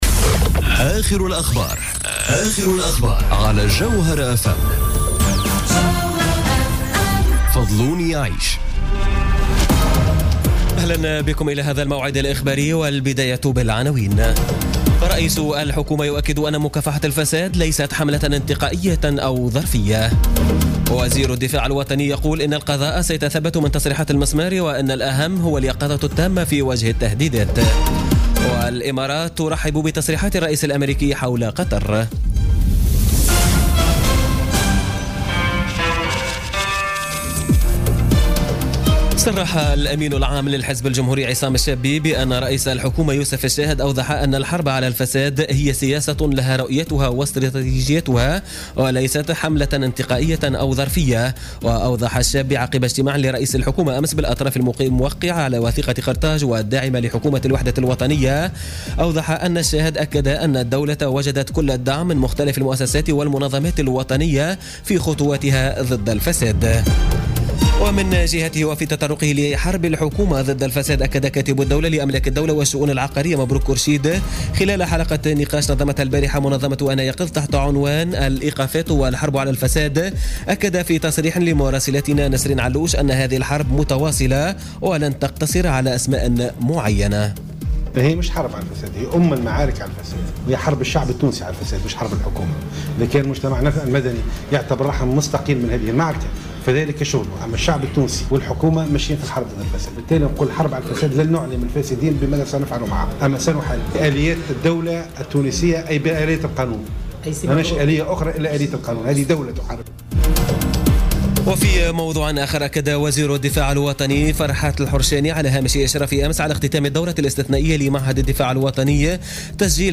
نشرة أخبار منتصف الليل ليوم السبت 10 جوان 2017